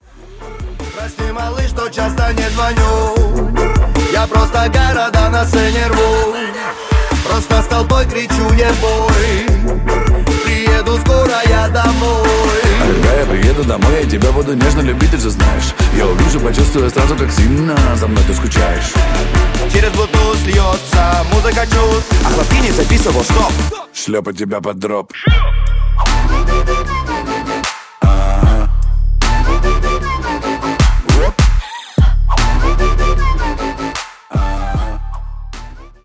• Качество: 128, Stereo
веселые
Trap
басы
качающие
Moombahton